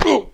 Hit3.wav